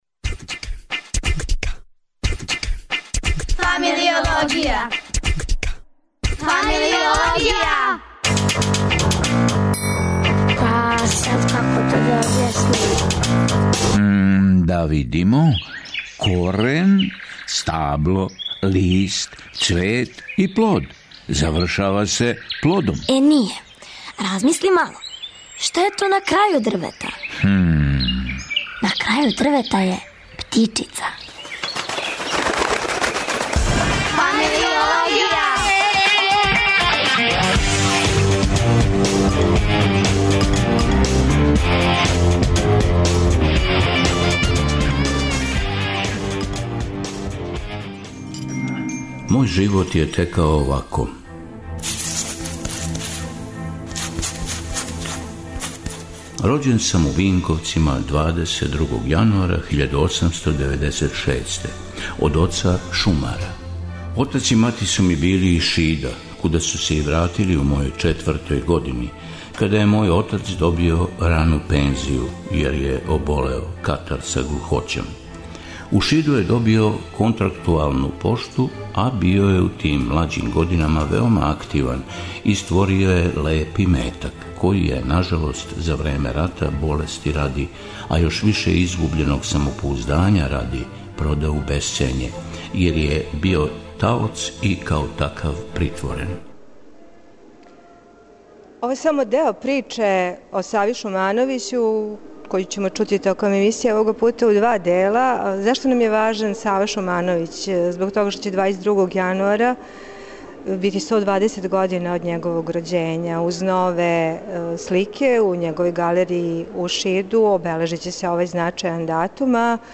Имамо Породичну причу Саве Шумановића коју смо снимили у Шиду.
Емисија се реализује уживо из Галерије САНУ. Биће то разговор о уметнику, уметничком (измишљеном) претку, стварном животу и стварној породици, сну о лету и полетању...